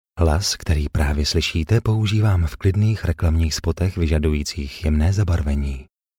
Sprecher tschechisch für TV / Rundfunk / Industrie.
Sprechprobe: Werbung (Muttersprache):
Professionell voice over artist from Czech.